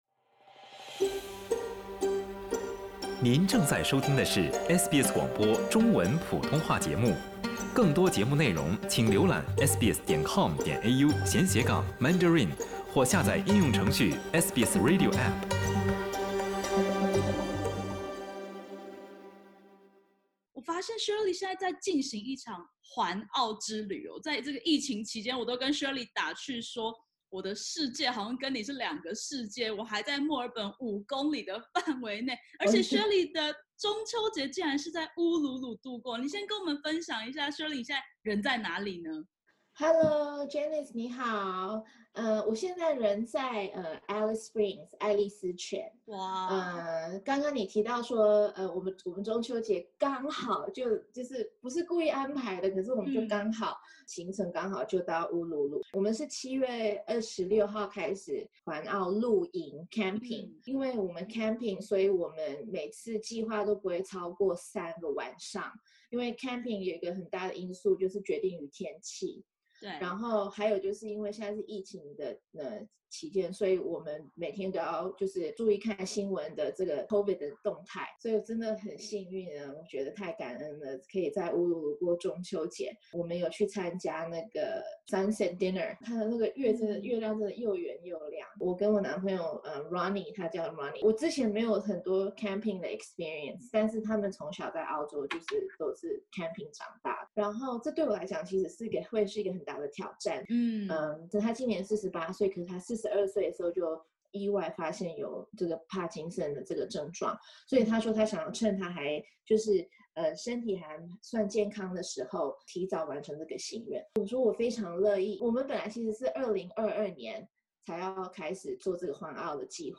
点击首图收听完整采访音频。